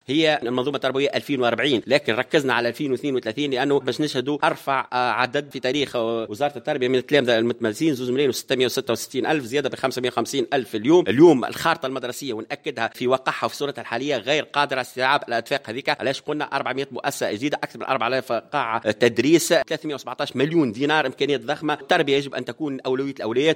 وأضاف في تصريح اليوم لمراسل "الجوهرة أف أم" على هامش ندوة صحفية عقدتها وزارة التربية، أن الخارطة المدرسية لم تعد قادرة على استيعاب هذه الاعداد، مؤكدا أنه سيتم إحداث 400 مؤسسة تربوية جديدة (أكثر من 4 آلاف قاعة تدريس).